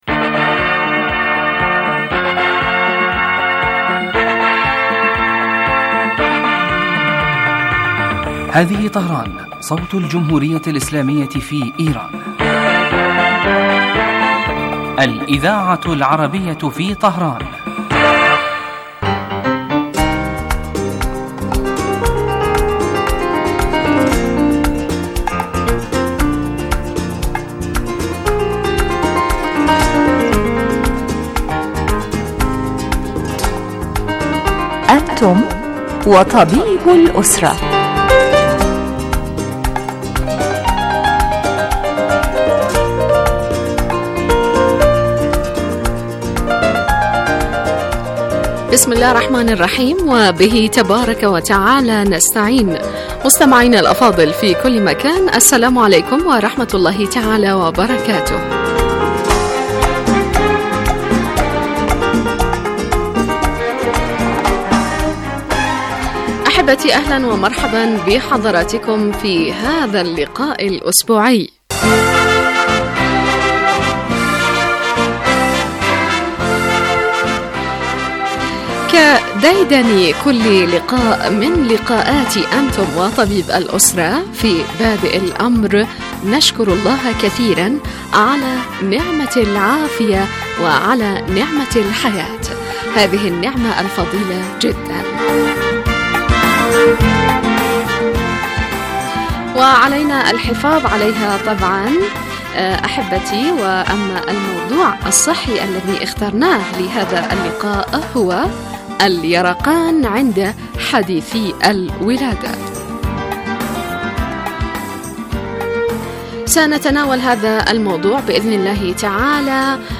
يتناول البرنامج بالدراسة والتحليل ما يتعلق بالأمراض وهو خاص بالأسرة ويقدم مباشرة من قبل الطبيب المختص الذي يرد كذلك علي أسئلة المستمعين واستفساراتهم الطبية